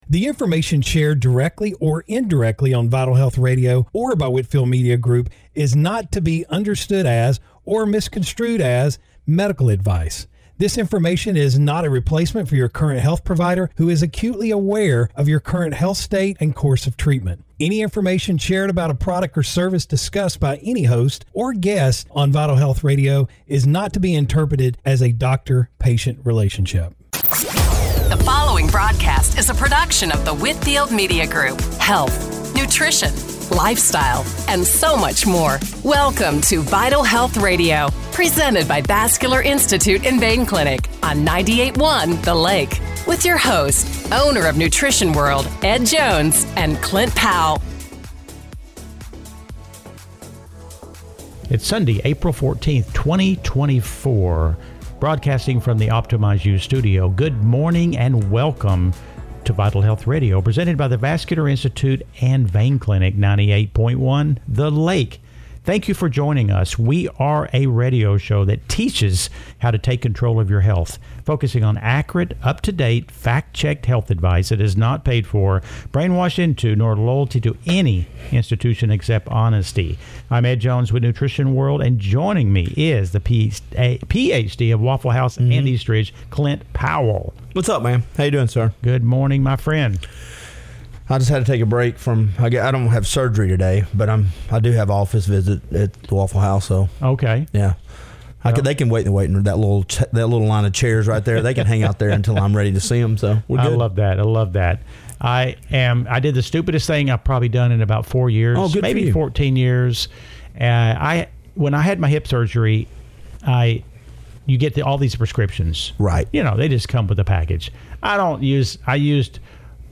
Radio Show – April 14, 2024 – Vital Health Radio